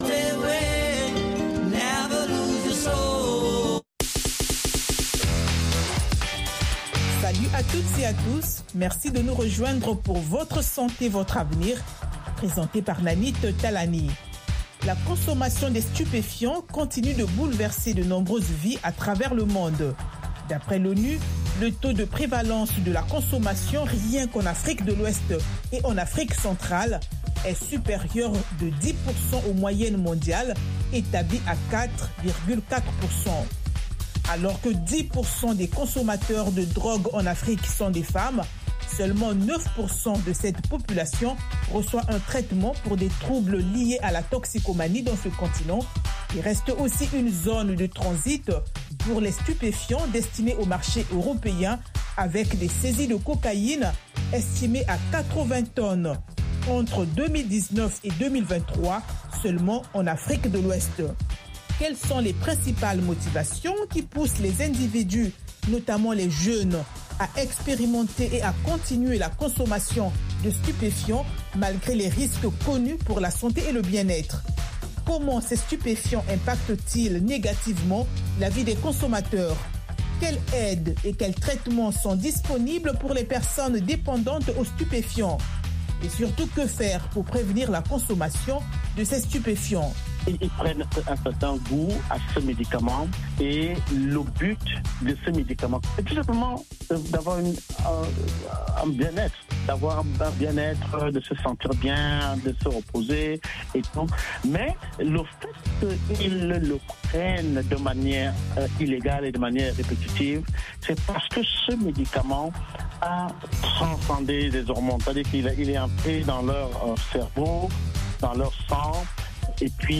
Fistules etc. Avec les reportages de nos correspondants en Afrique. VOA donne la parole aux personnes affectées, aux médecins, aux expert, aux parents de personnes atteintes ainsi qu’aux auditeurs.